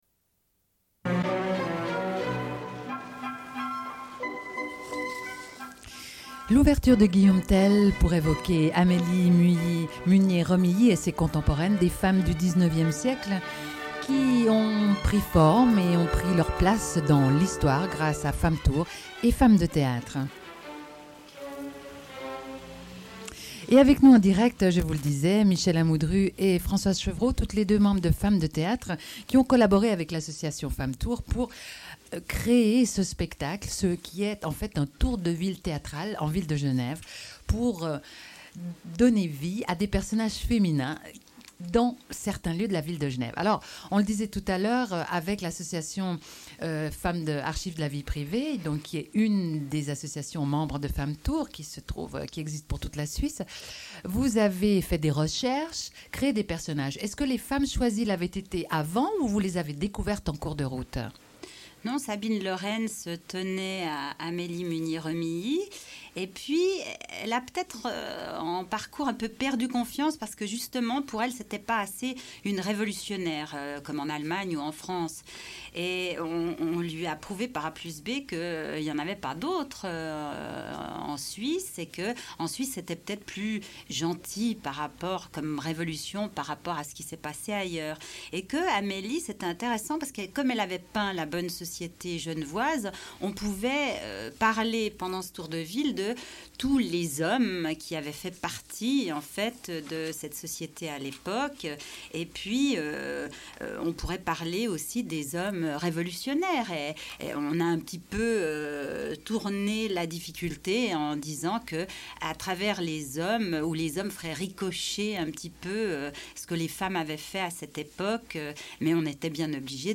Une cassette audio, face B
Radio Enregistrement sonore